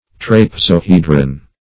Trapezohedron \Trap`e*zo*he"dron\, n. [NL., from trapezium + Gr.